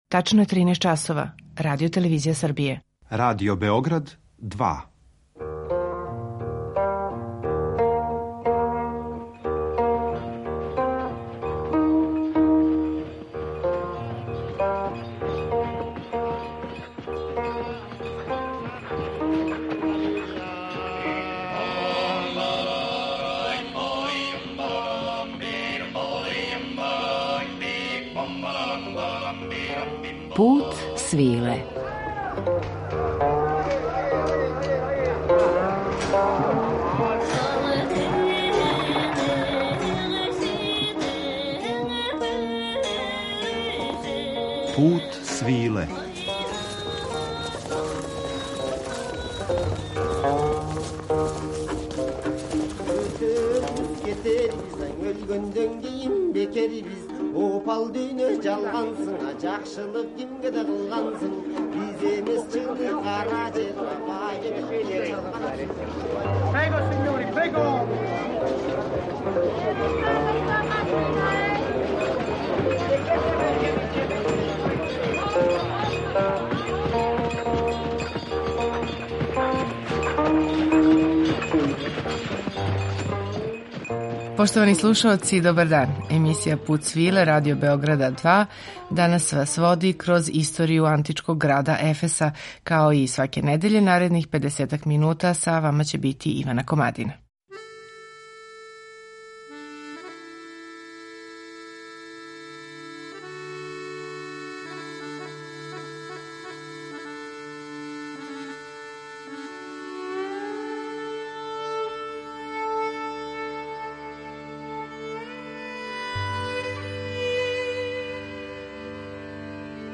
То је био разлог да музички део данашњег Пута свиле, који смо посветили историји овог античког града, препустимо грчкој певачици Савини Јанату, која је својим тумачењем осветлила различите музичке традиције народа који су у овом граду некада живели.